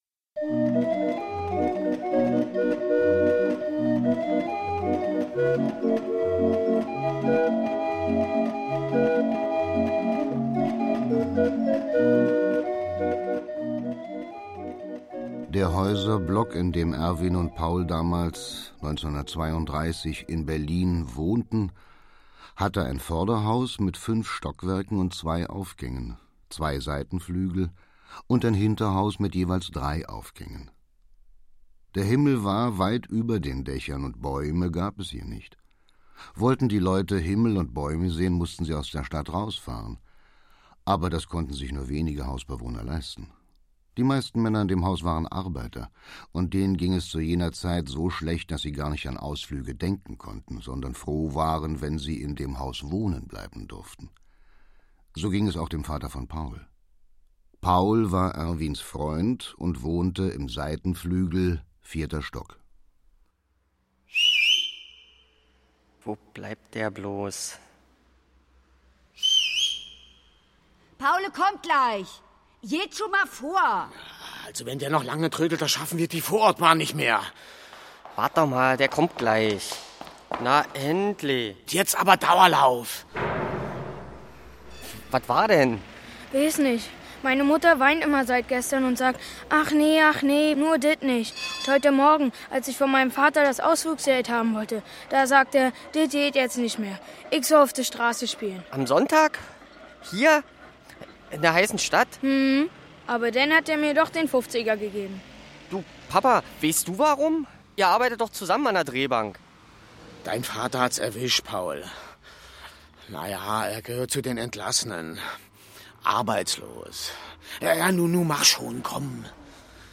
Schlagworte 30er Jahre / Dreißiger Jahre; Kinder-/Jugendliteratur • 30er Jahre; Kinder-/Jugendliteratur • Arbeitslosigkeit • Armut • Bande • Berlin • Berlin, Geschichte; Kinder-/Jugendliteratur • Familie • Freundschaft • Hörbuch; Hörspiel für Kinder/Jugendliche • Hörspiel • Kinder • Kinderklassiker • Klassiker • Mehrfamilienhaus • Mietshaus • Nachbarschaft • Sozialdrama